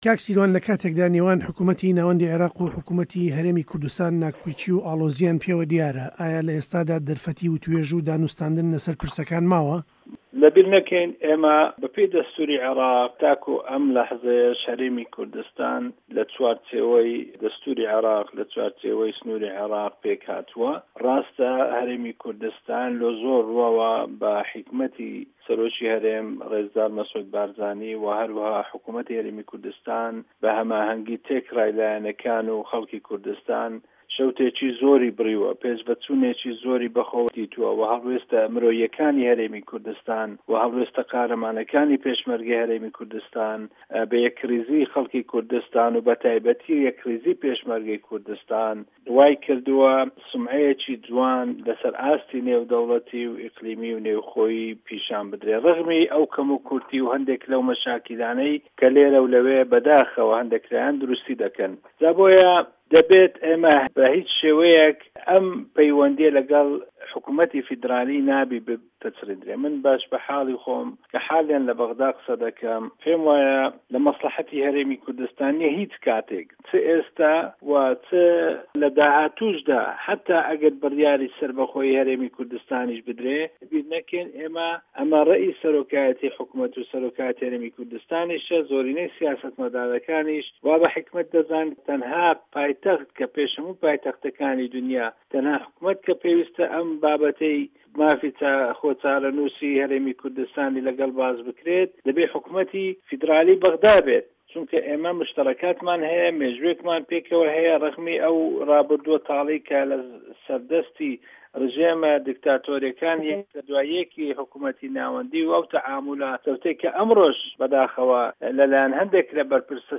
لەمبارەیەوە دەنگی ئەمریکا گفتووگۆی لەگەڵ سیروان عەبدوڵا سیرینی ئەندامی پەرلەمانی عێراق سازداوە.
گفتووگۆ له‌گه‌ڵ سیروانی سیرینی